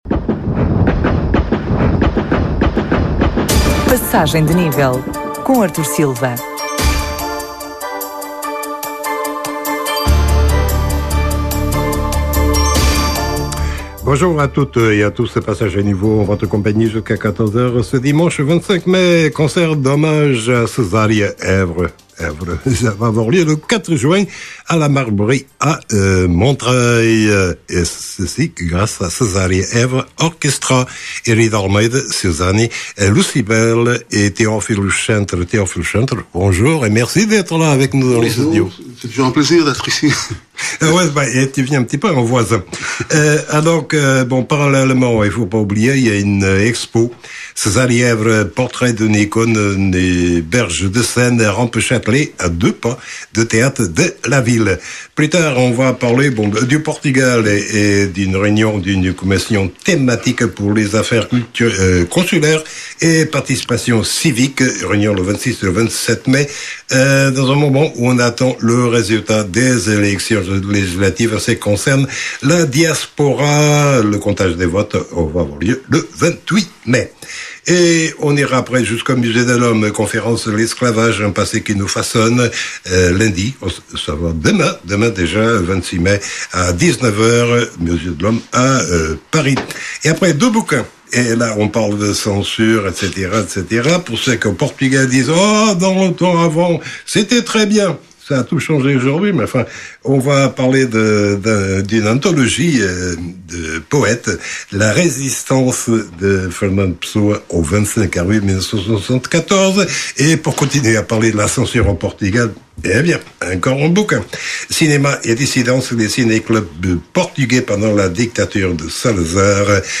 magazine de informação na Rádio Alfa